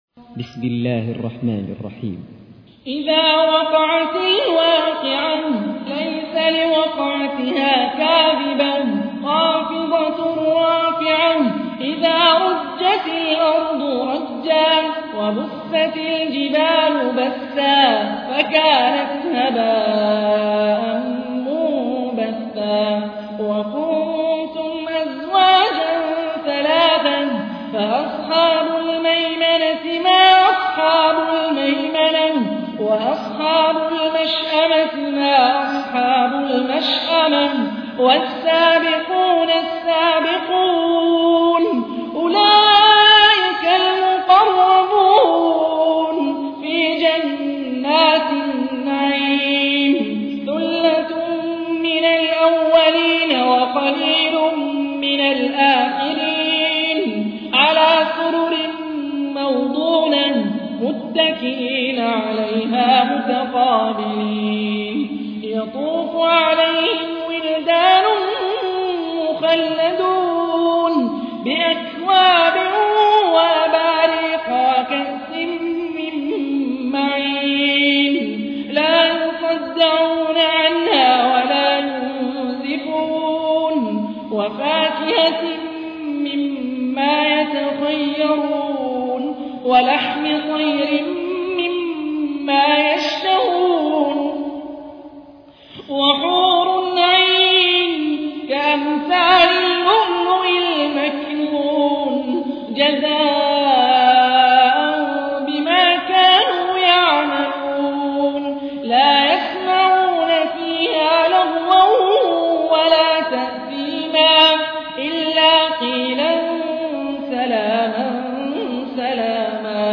تحميل : 56. سورة الواقعة / القارئ هاني الرفاعي / القرآن الكريم / موقع يا حسين